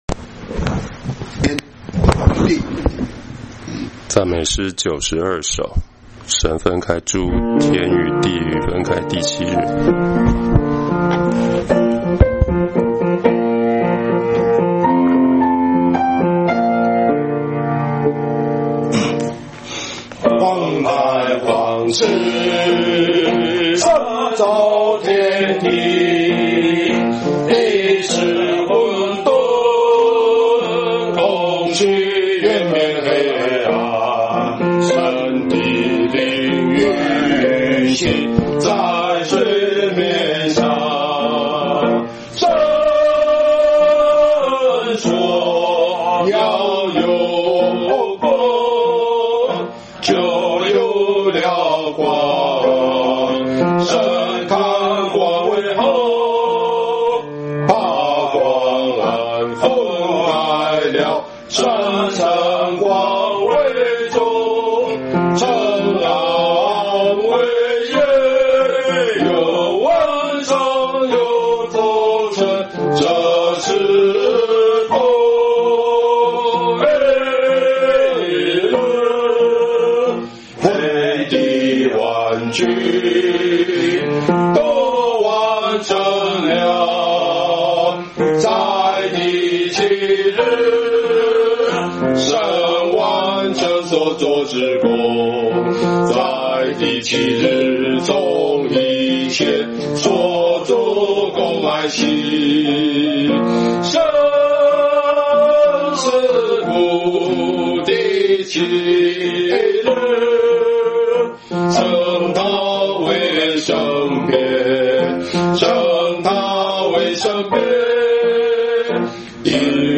詩歌頌讚